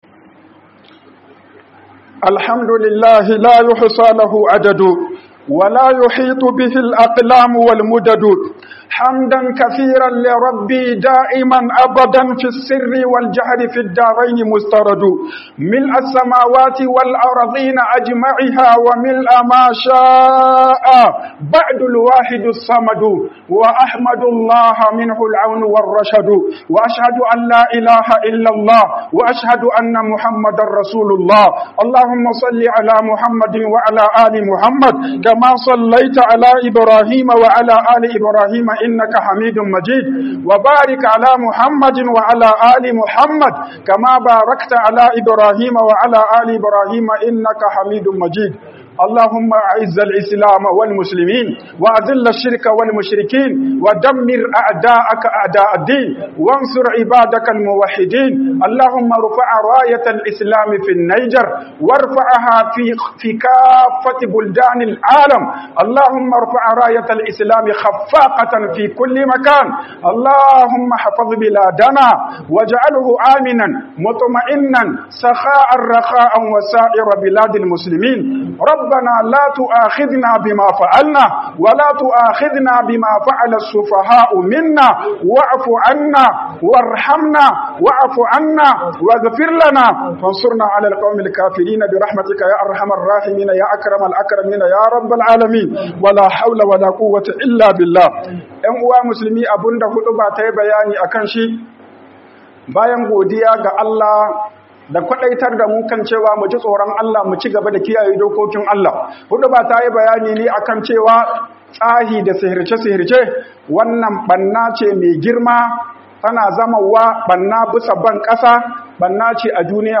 MAIDA MARTANI GA BOKAYE - HUƊUBOBIN JUMA'A